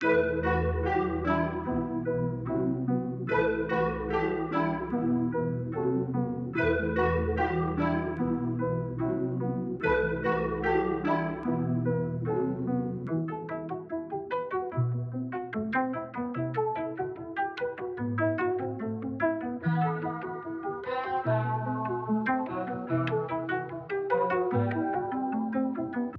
haram_147bpm_oz.wav